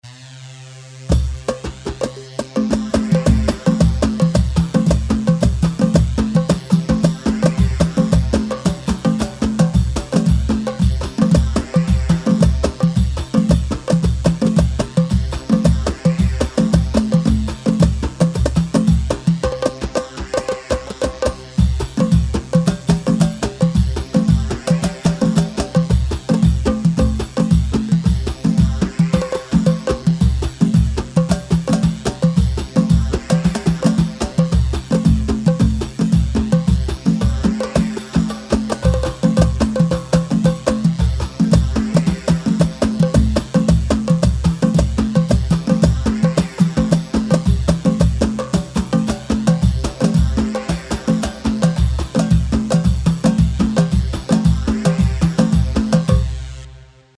Khbeiti 3/4 167 خبيتي
Khbeiti-3-4-167-L.mp3